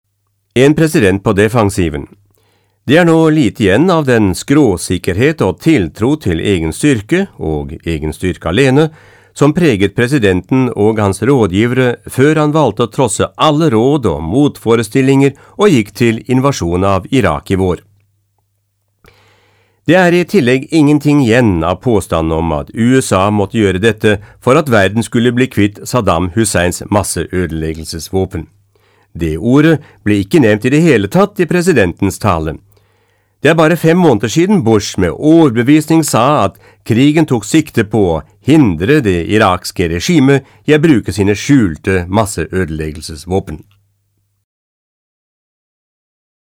Sprecher Norwegisch.
Kein Dialekt
Sprechprobe: Industrie (Muttersprache):
norvegian voice over artist